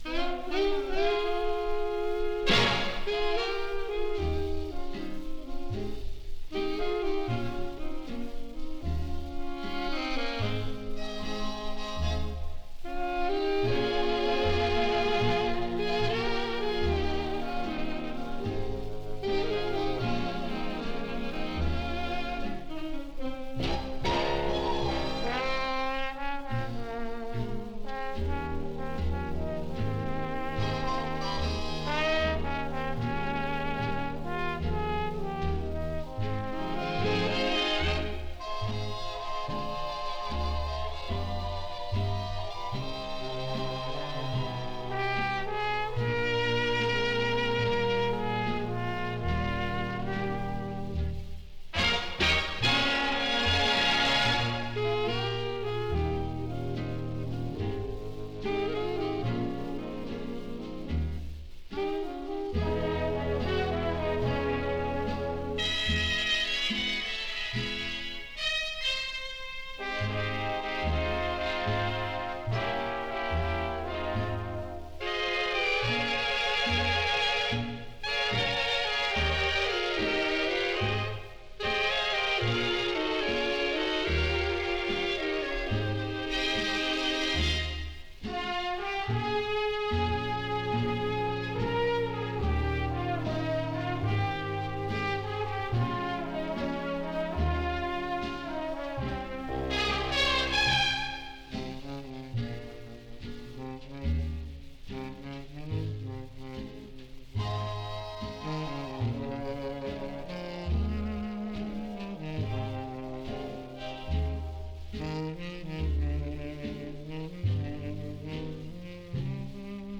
some 78 rpm sides from the late big band era